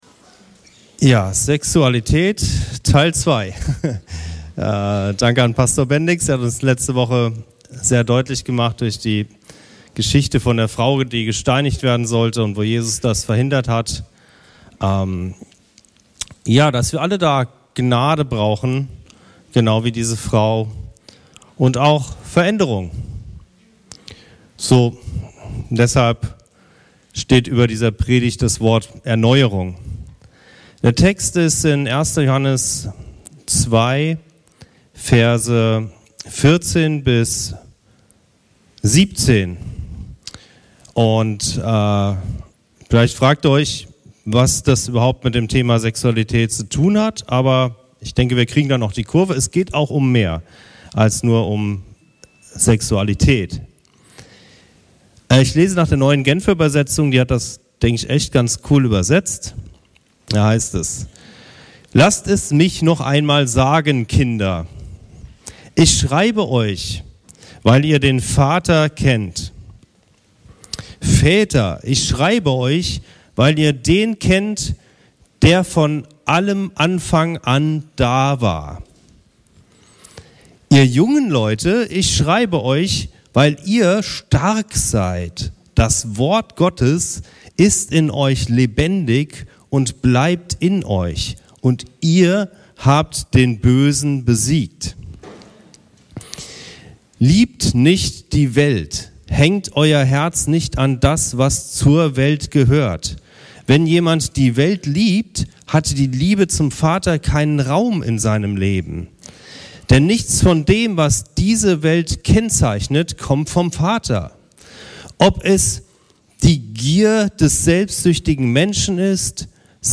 Predigtreihe Sex Teil 2 – Erneuerung ~ Anskar-Kirche Hamburg- Predigten Podcast